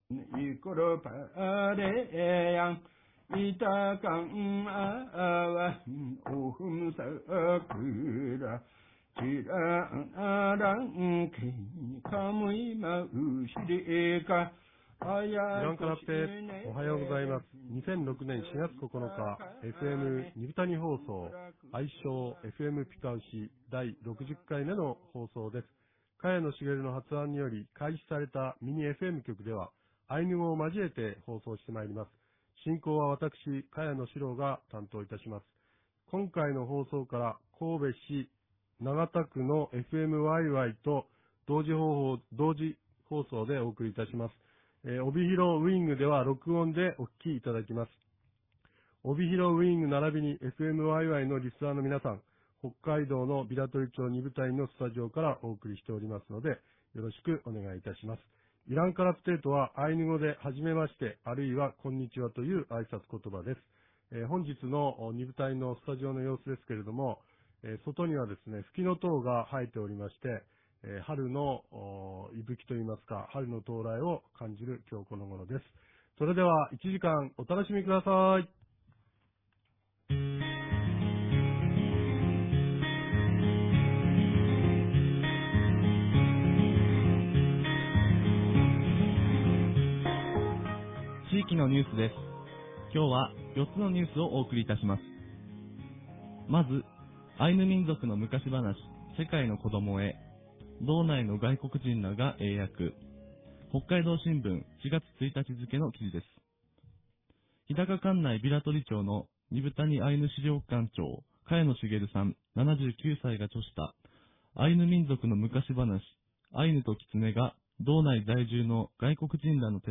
地域のニュース
インタビューコーナー